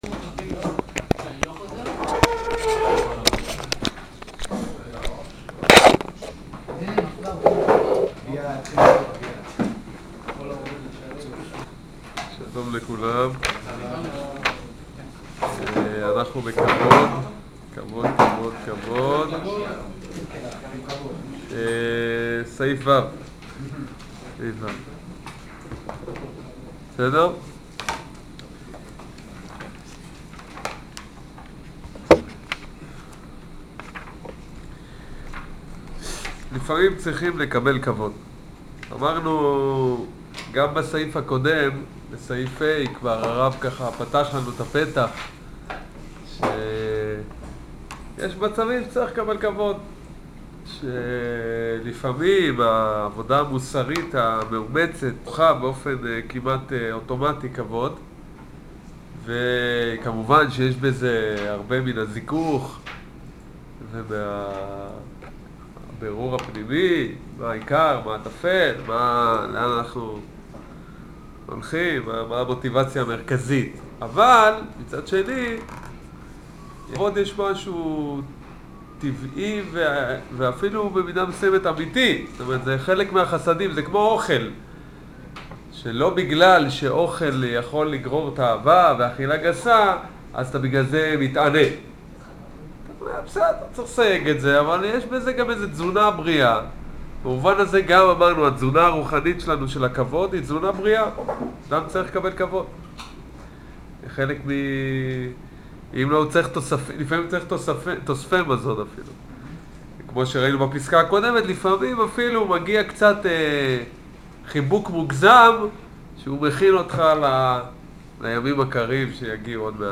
שיעור כבוד